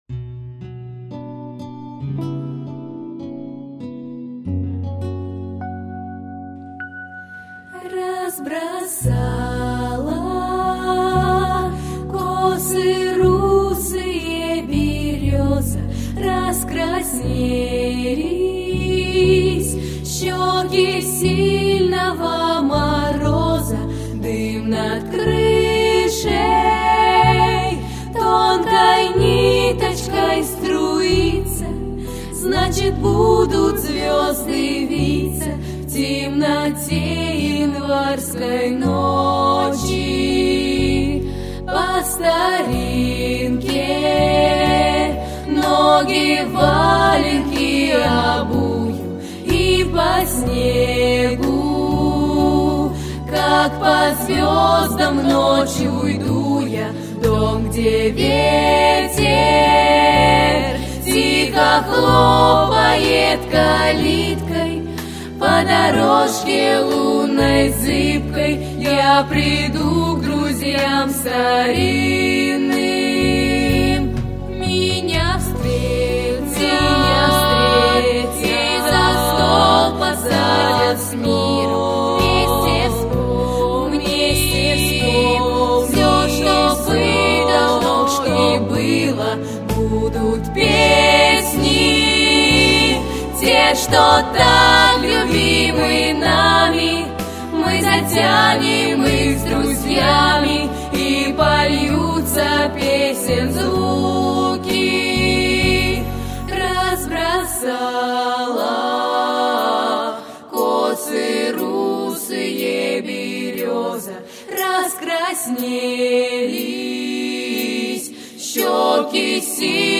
sskiy_folk_razbrosala_kosy_sye_bereza.mp3